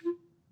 Clarinet / stac
DCClar_stac_F3_v1_rr2_sum.wav